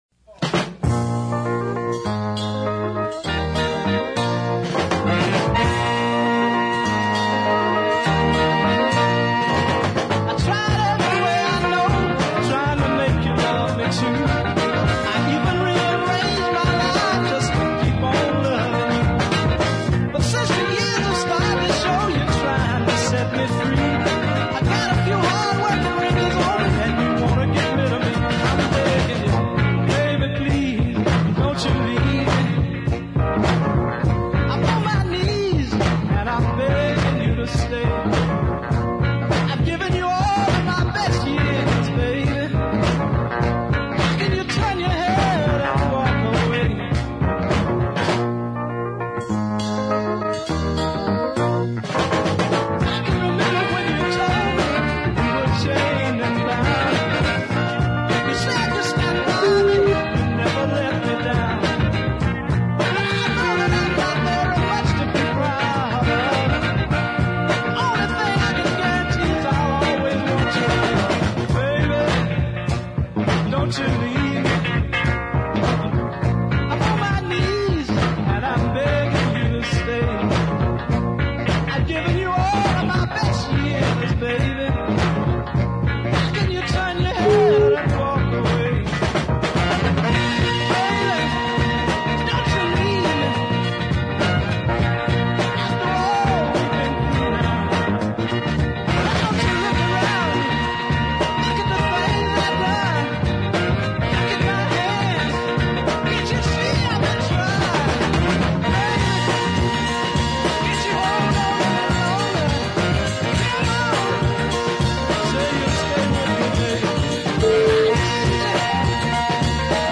mid paced beater
great chugging drumming
some superb electric piano fills
those wonderful horns
sings some fine lines in a light high baritone